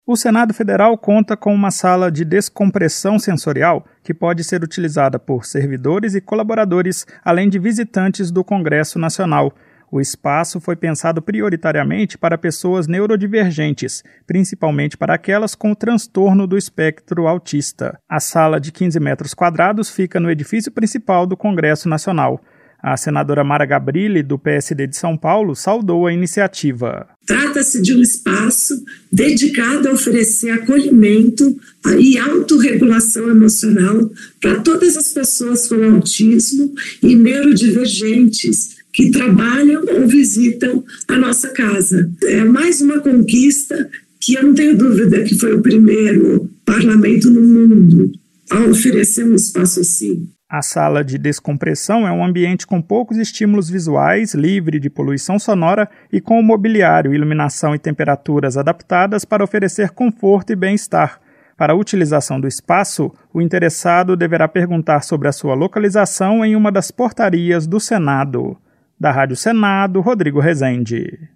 A senadora Mara Gabrilli (PSD-SP) destacou que a iniciativa promove acolhimento e autorregulação emocional.